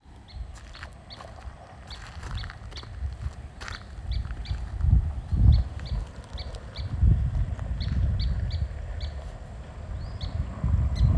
Couch’s Kingbird